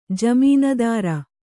♪ jamīndāra